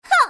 slayer_f_voc_attack01_e.mp3